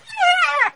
c_hyena_hit2.wav